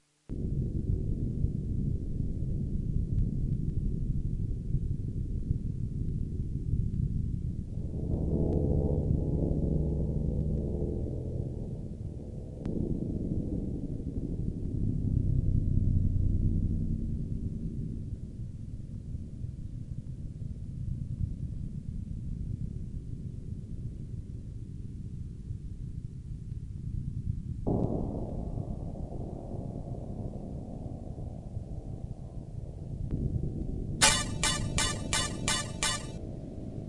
描述：playing around with some new VSTplugins. Created this ambient electronic drone
标签： ambient noisescape drone soundscape
声道立体声